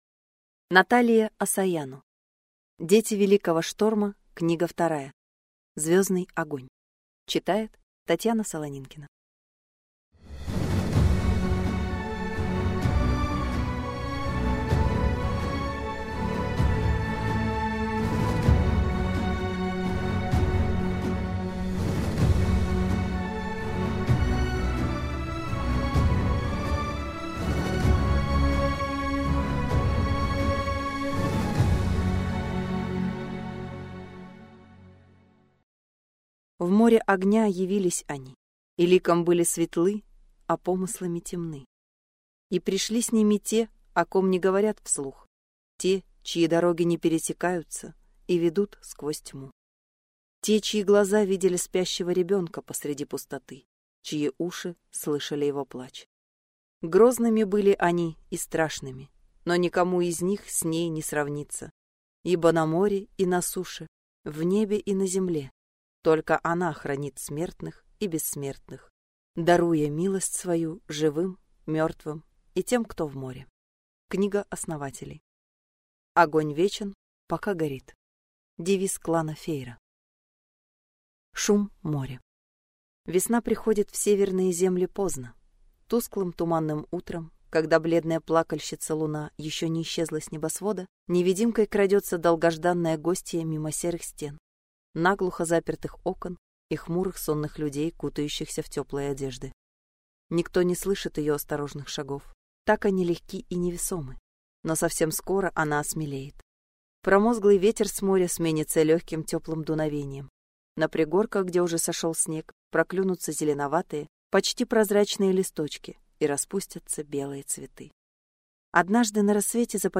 Аудиокнига Звёздный огонь | Библиотека аудиокниг